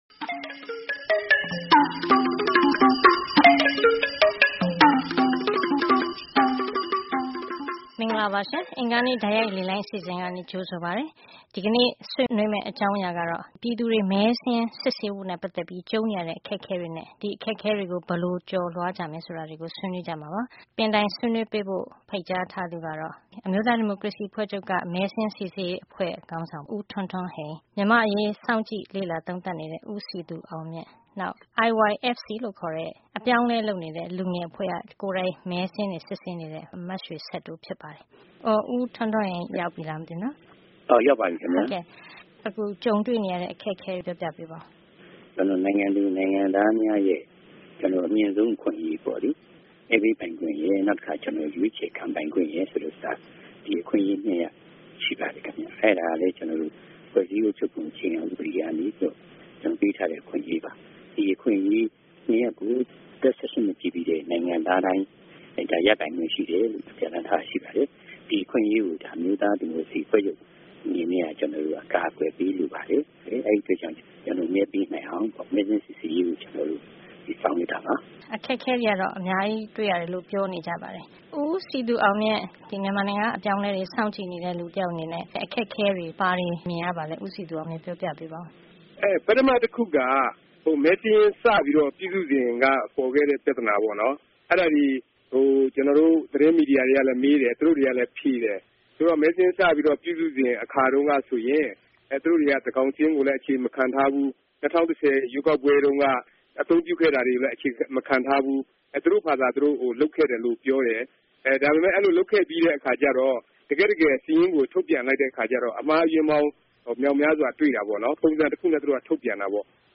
09-22-15 Tuesday call in show- problems in voter list checking and ways to solve